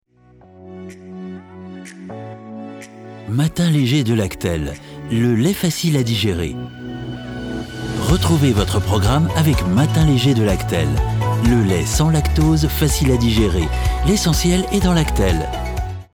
Male
French (European), French (Parisienne)
I work from my own recording studio and accept all directed audio and video sessions.
It can be enveloping, full, sthenic if needed, mysterious, calm, confident, reassuring, conversational...
Television Spots